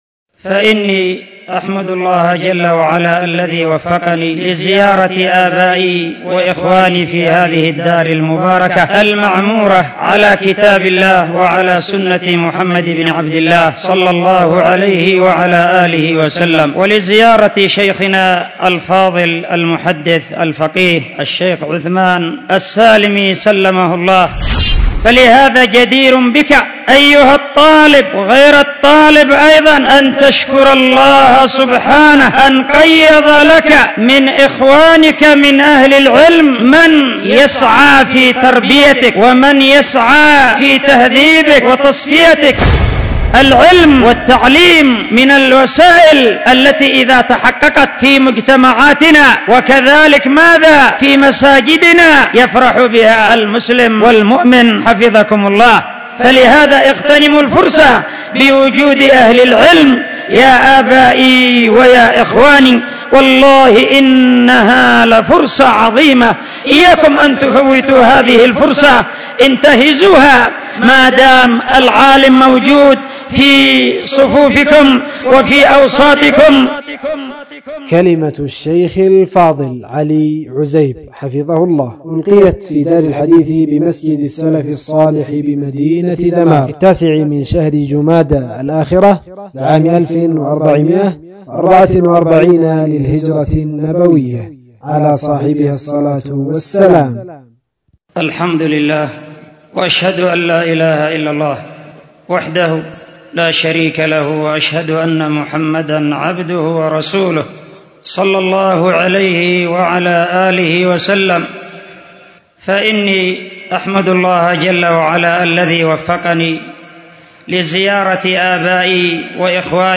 ألقيت في دار الحديث بمسجد السلف الصالح بذمار